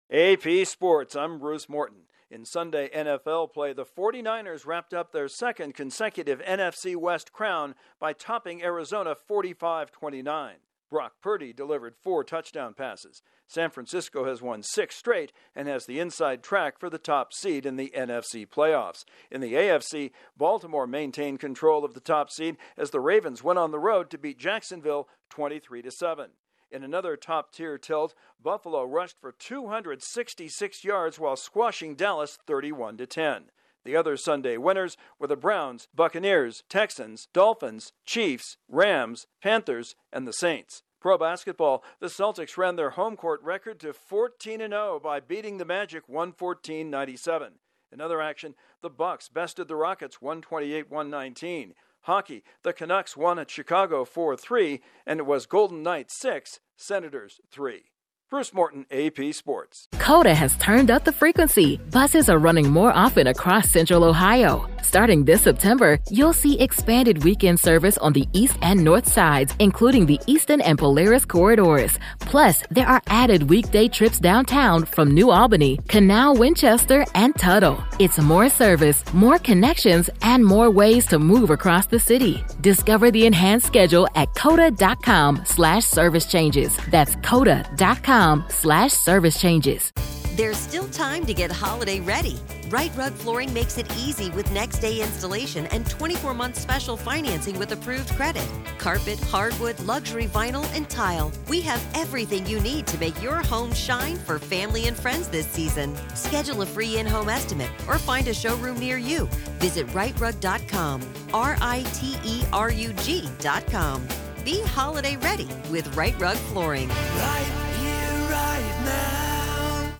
The 49ers and Ravens hold on to the top seed for the NFC and AFC playoffs, respectively, while the Bills score an emphatic victory over the Cowboys and the Celtics remain undefeated on their home floor. Correspondent